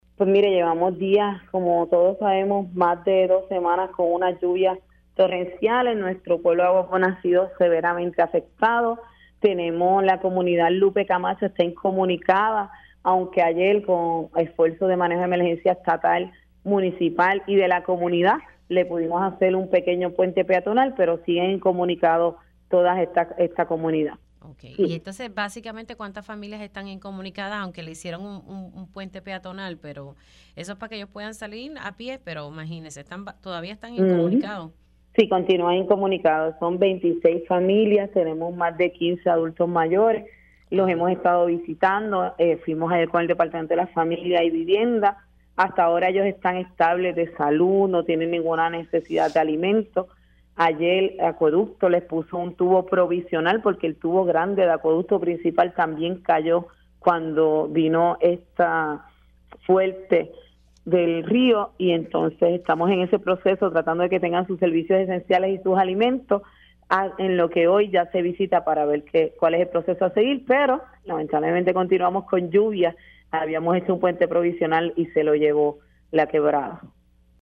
La alcaldesa de Aguas Buenas, Karina Nieves indicó en Pega’os en la Mañana que 26 familias de la comunidad Lupe Camacho están incomunicadas luego de que las intensas lluvias este fin de semana agravaron un socavón en una carretera del área.